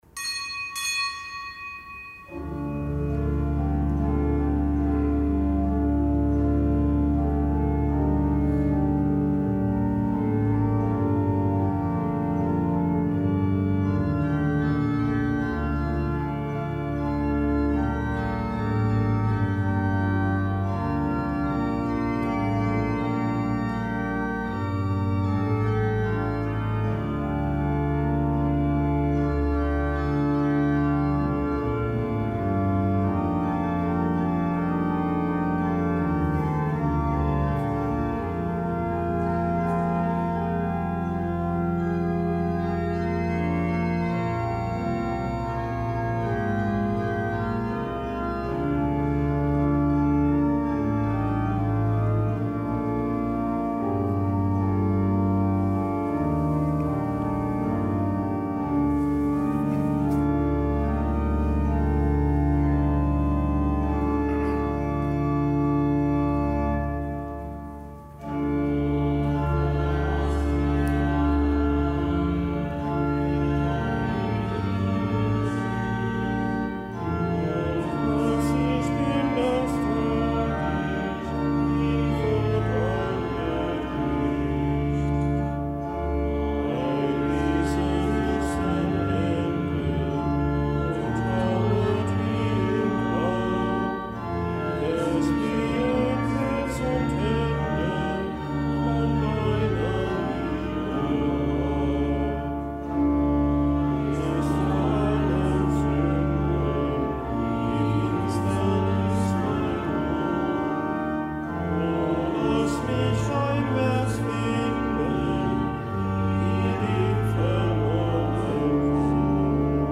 Kapitelsmesse am Gedenktag des Heiligen Stanislaus
Kapitelsmesse aus dem Kölner Dom am Gedenktag des Heiligen Stanislaus, Bischof von Krakau, Märtyrer.